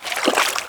sound / steps / water4.wav
water4.wav